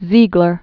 (zēglər, tsē-), Karl Waldemar 1898-1973.